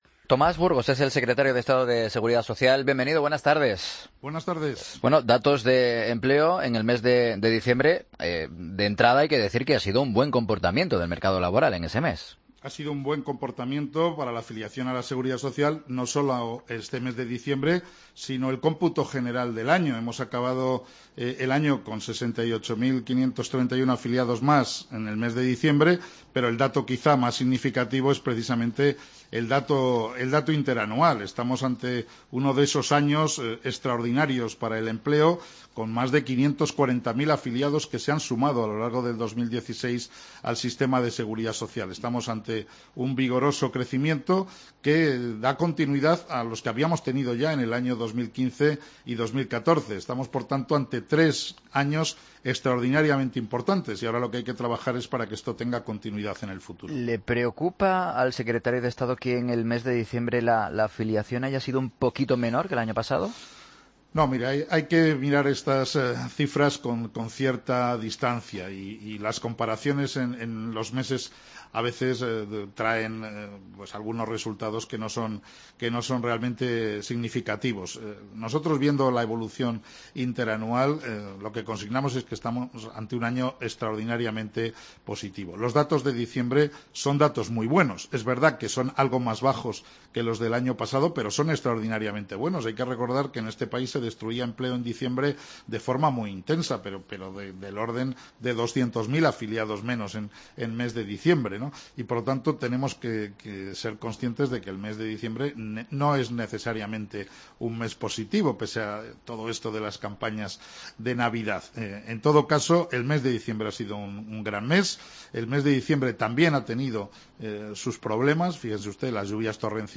Tomás Burgos, secretario de Estado de Seguridad Social en 'Mediodía COPE'